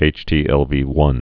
(āchtē-ĕlvē-wŭn)